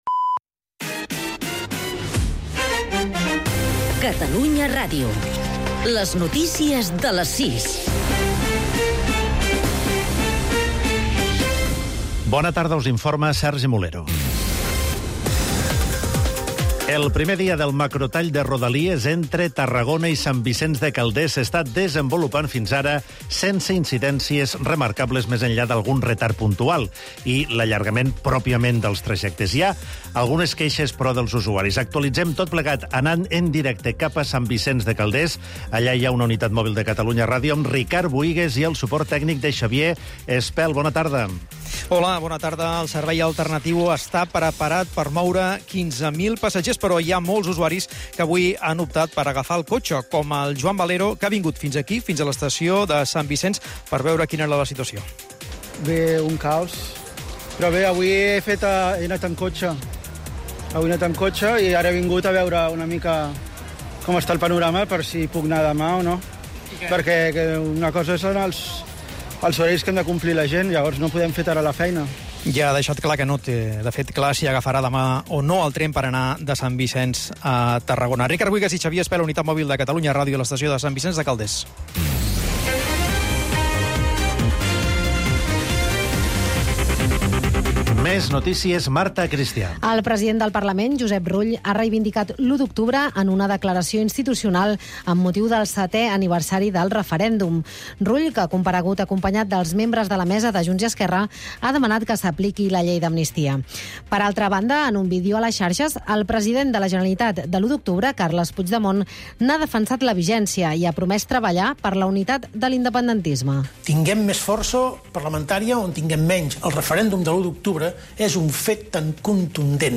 Un programa que, amb un to proper i dists, repassa els temes que interessen, sobretot, al carrer. Una combinaci desacomplexada de temes molt diferents.